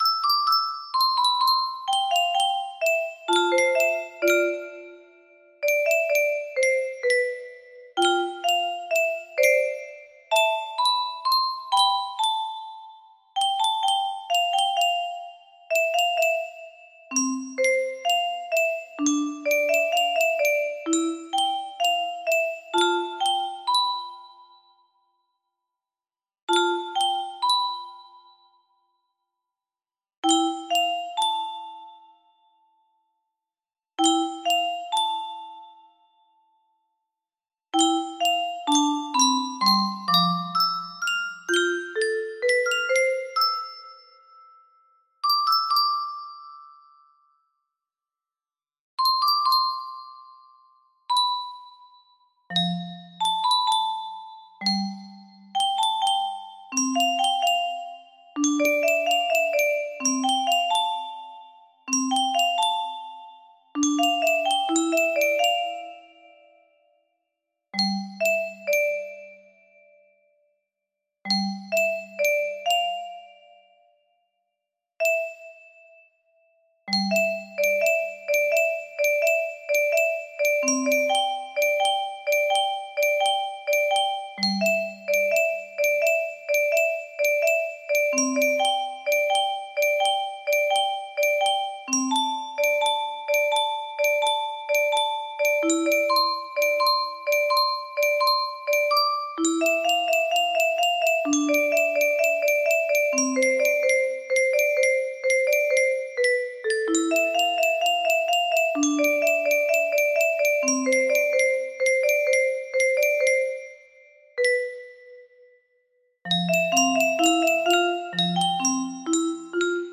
new music box melody
Grand Illusions 30 (F scale)